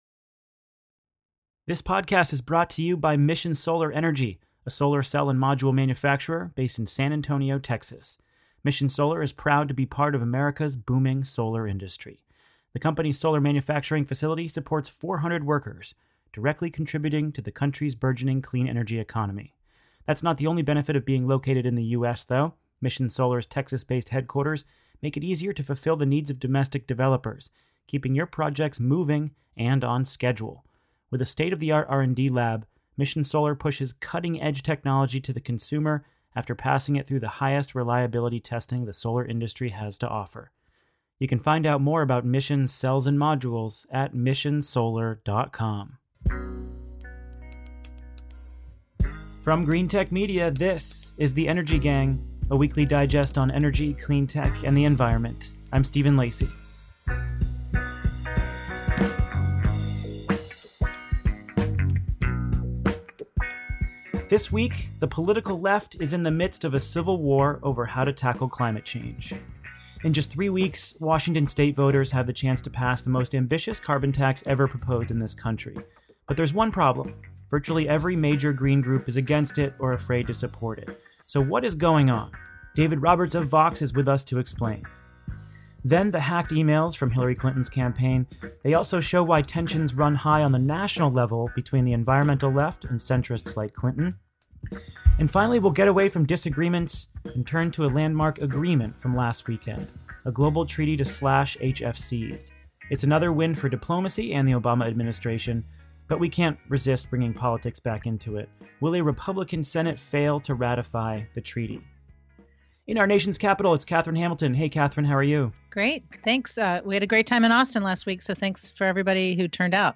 This week’s episode of The Energy Gang podcast includes a very interesting discussion of a proposed carbon tax on the ballot in Washington State. The logic behind it has explicitly been to forge post-partisan consensus instead of a left-wing coalition.